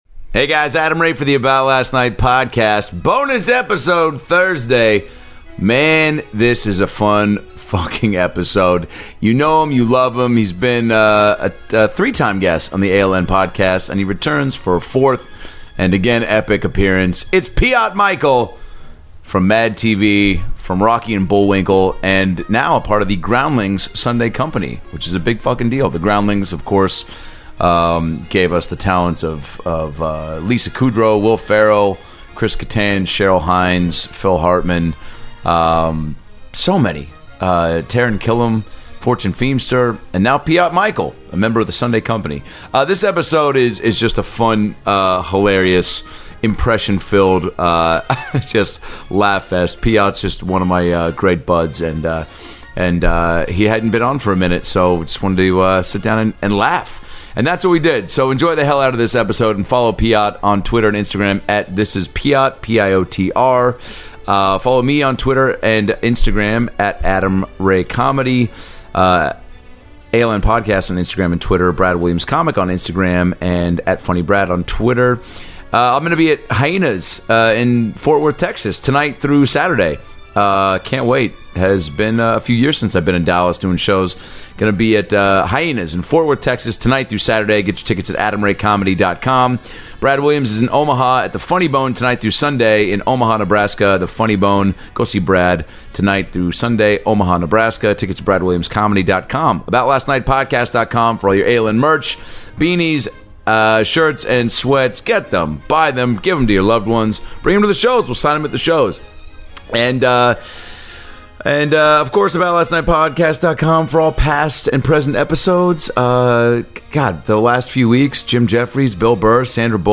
From his new job at The Groundlings, to voicing the villain in ROCKY & BULLWINKLE, and appearances by Morgan Freeman, Steve Buscemi and Jeff Goldblum, this is a non-stop laugh filled episode!